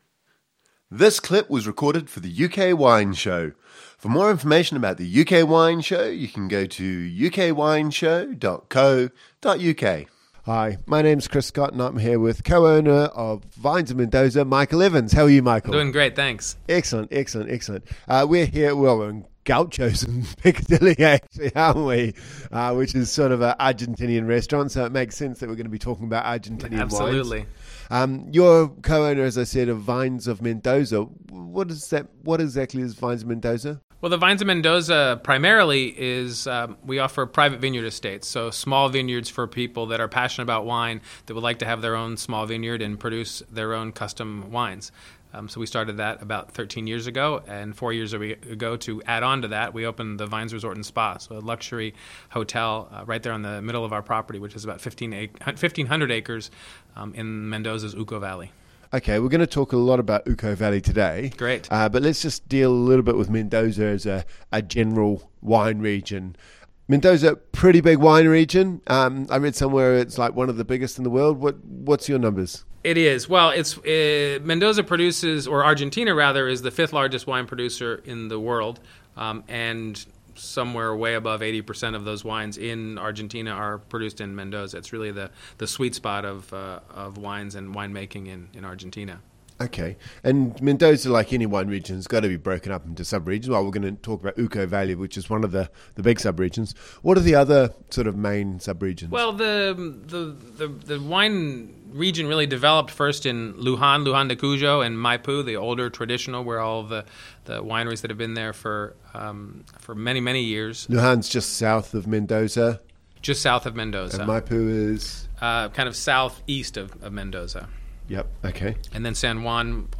In this second interview we talk about Mendoza and the Uco Valley.